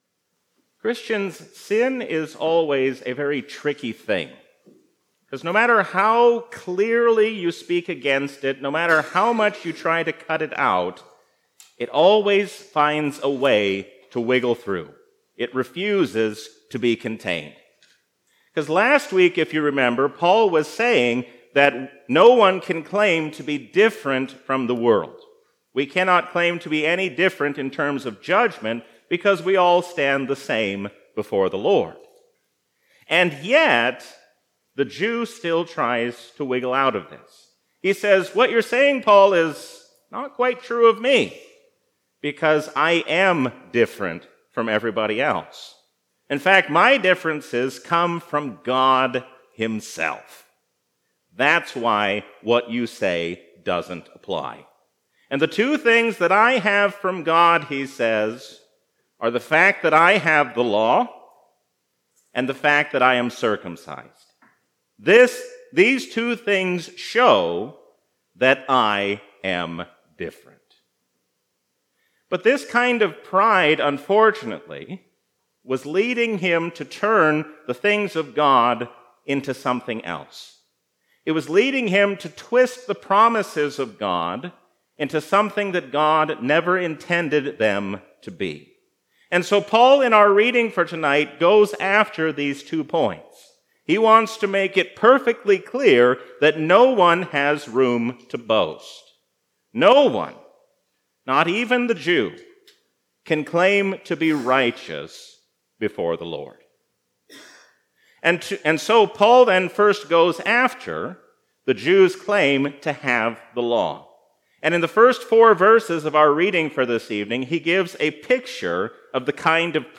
A sermon from the season "Easter 2022." Let us be Christians not only in our words, but also in what we do.